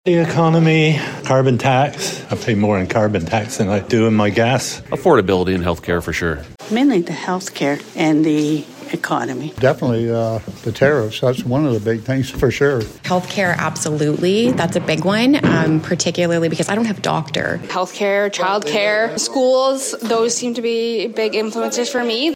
From healthcare and education to housing affordability, the priorities of each voter we talked to were relatively similar, as we hit the streets to hear from you.
feb-27-election-streeter-web.mp3